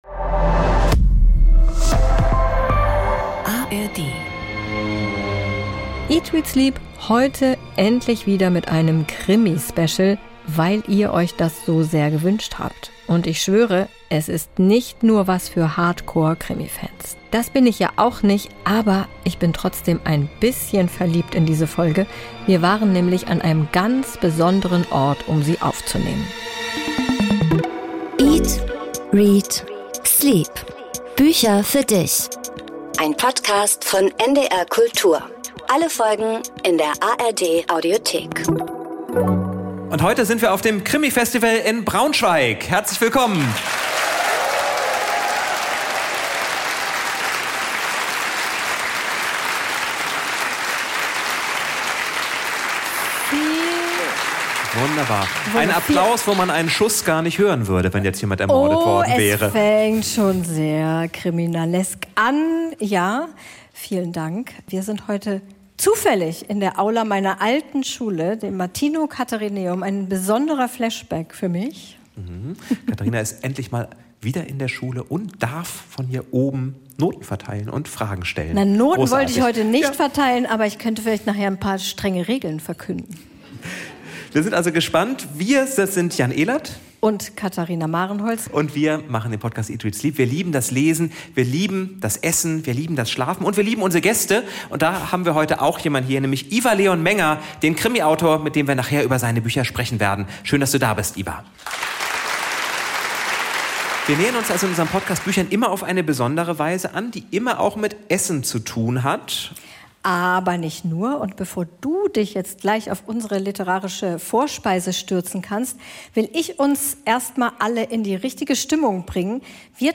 Eine Aufzeichnung vom Krimifestival in Braunschweig.